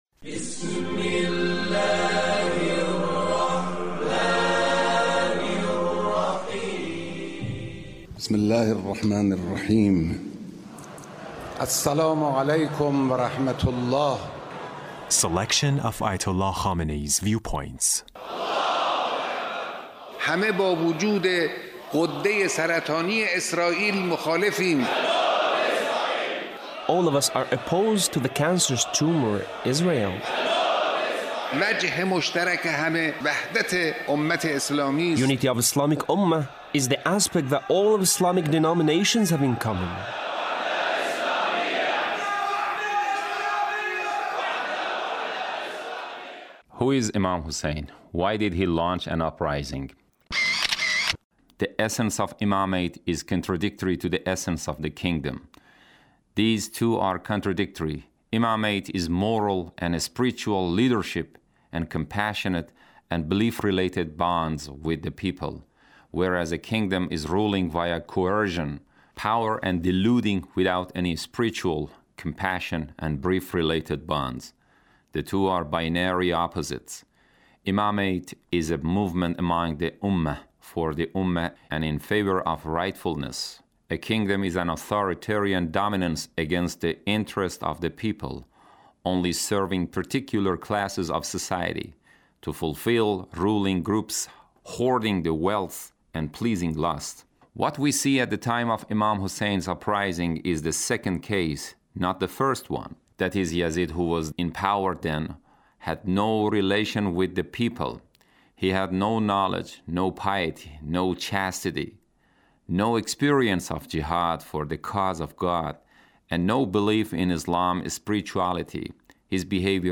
Leader's Speech (1768)